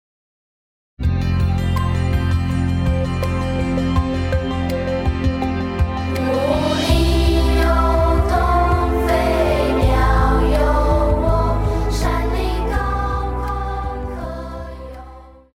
Christian
Pop chorus,Children Voice
Band
Christmas Carols,Hymn,POP,Christian Music
Voice with accompaniment